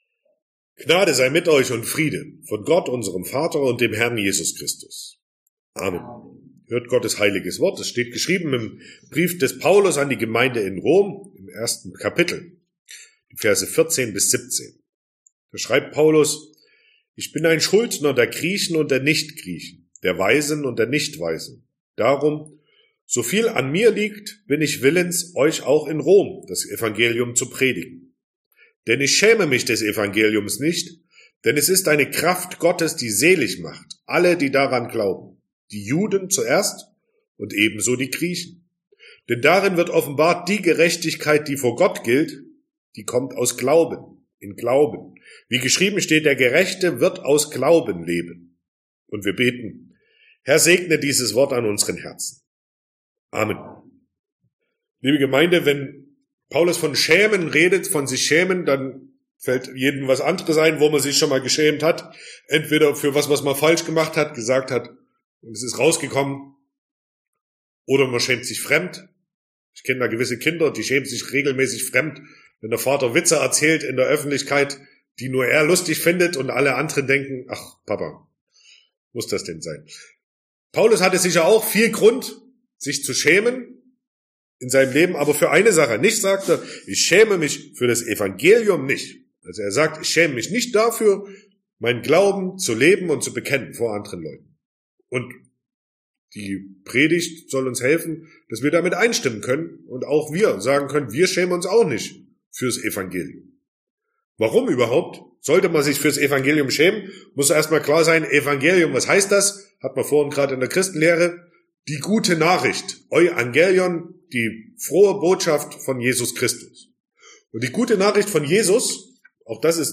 Sonntag nach Epiphanias Passage: Römer 1, 14-17 Verkündigungsart: Predigt « 2.